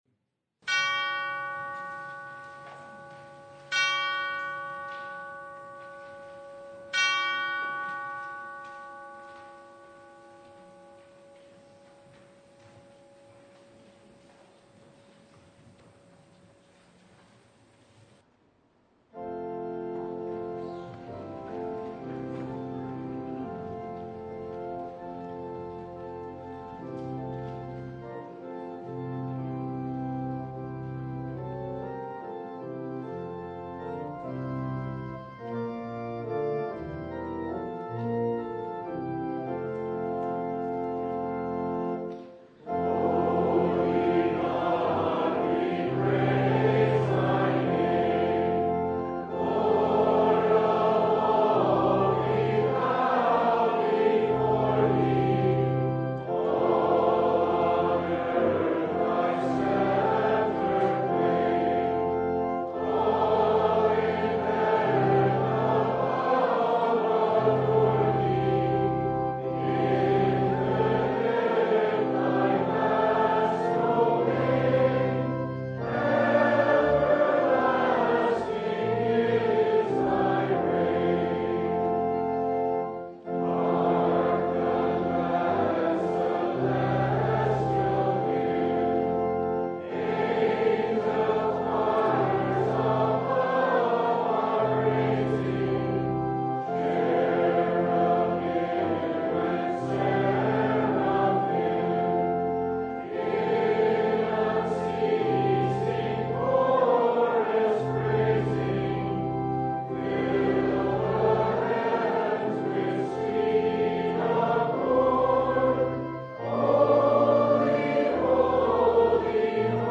Full Service